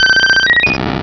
Cri d'Aquali dans Pokémon Rubis et Saphir.